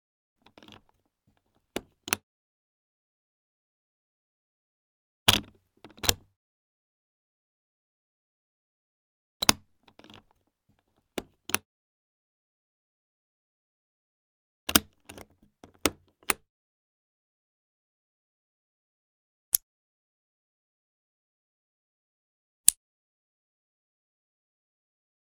household
Flight Case Unlock Metal Clasp Latch